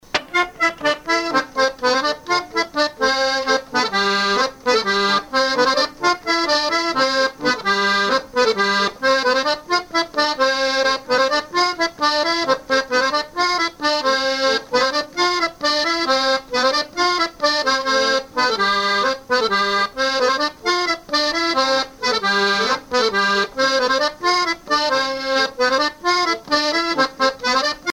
musique à danser à l'accordéon diatonique
Pièce musicale inédite